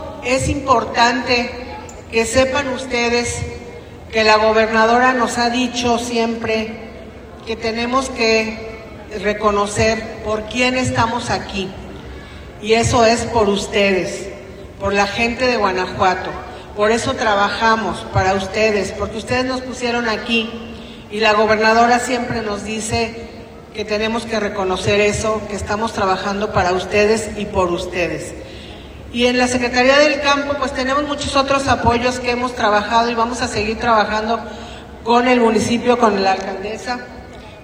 AudioBoletines
Marisol Suárez Correa, secretaria del campo